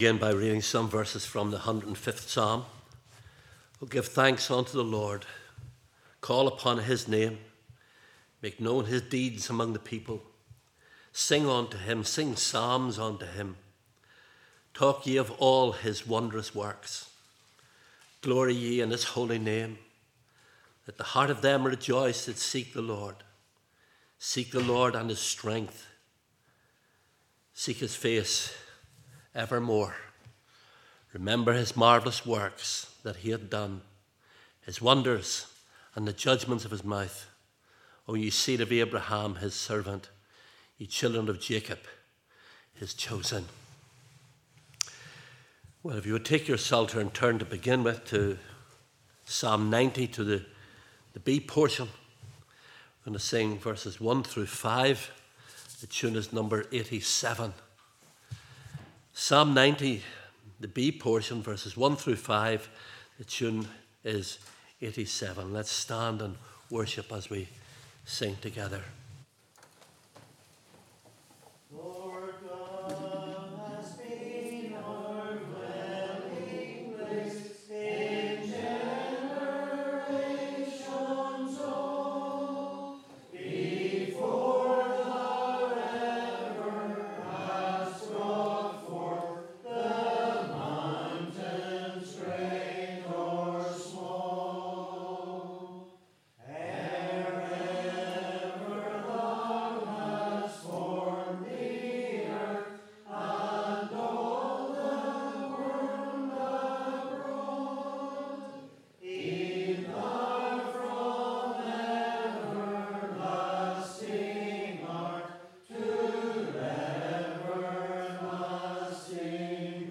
17 Service Type: Evening Service « Precious Things Faith